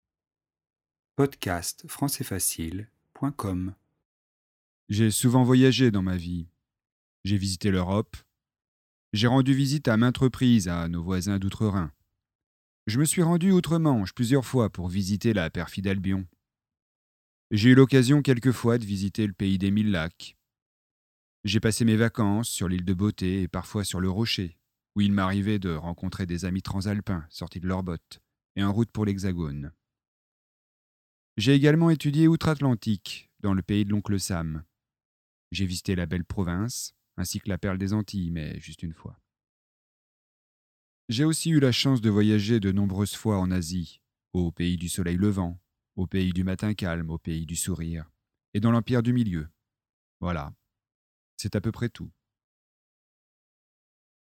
Vitesse normale